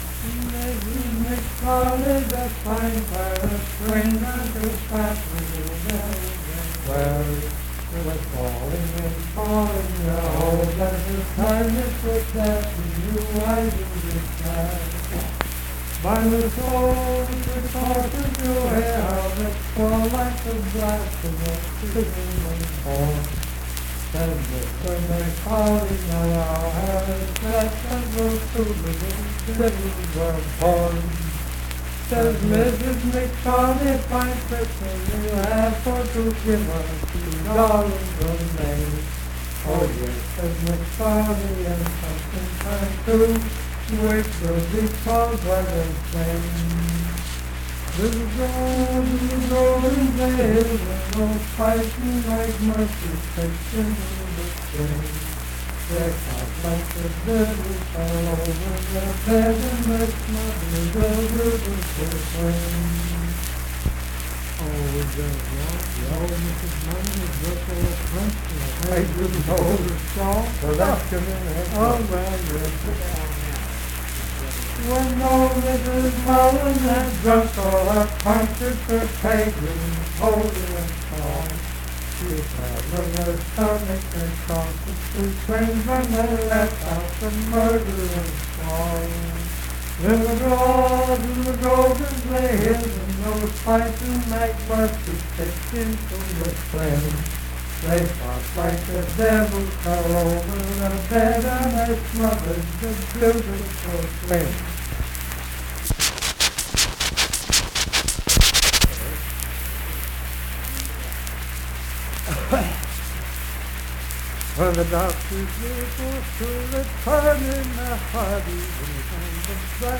Unaccompanied vocal music
Ethnic Songs
Voice (sung)
Richwood (W. Va.), Nicholas County (W. Va.)